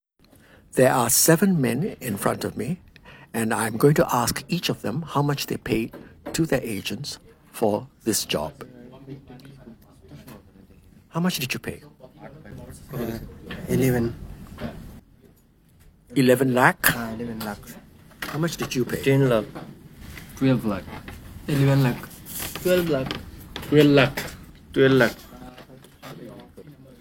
RSP_seven_workers_agent_fees.wav